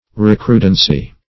Recrudency \Re*cru"den*cy\ (r[-e]*kr[udd]"den*s[y^])
recrudency.mp3